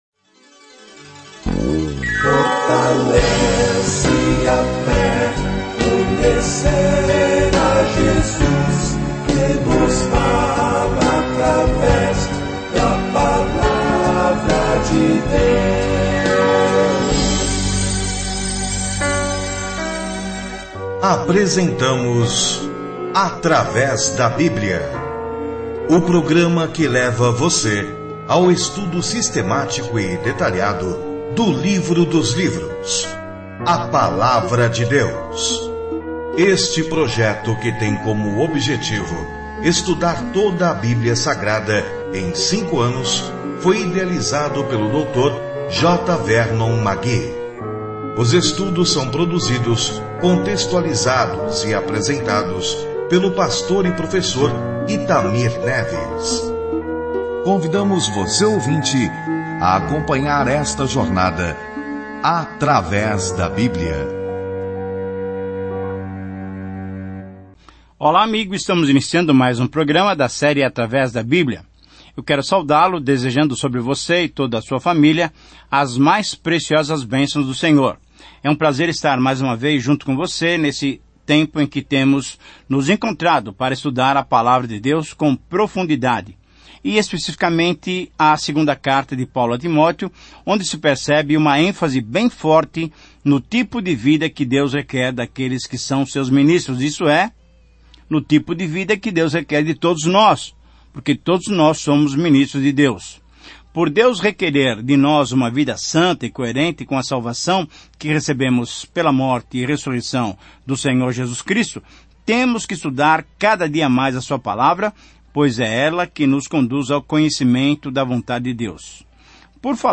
As Escrituras 2Timóteo 4:1-8 Dia 7 Começar esse Plano Dia 9 Sobre este Plano A segunda carta a Timóteo exorta o povo de Deus a defender a palavra de Deus, a guardá-la, pregá-la e, se necessário, sofrer por ela. Viaje diariamente por 2 Timóteo enquanto ouve o estudo em áudio e lê versículos selecionados da palavra de Deus.